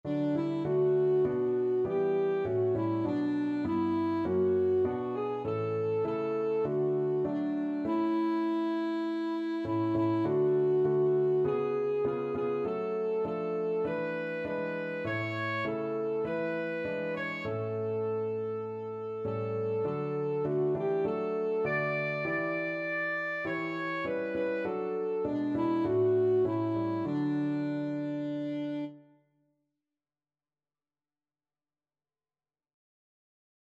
Alto Saxophone
4/4 (View more 4/4 Music)
Classical (View more Classical Saxophone Music)